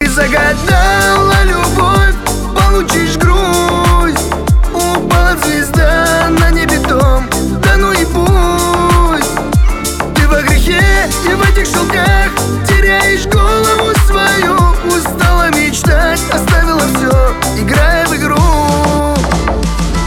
шансон
поп